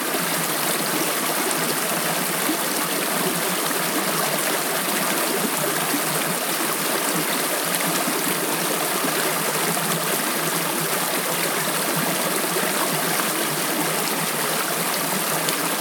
river-2.ogg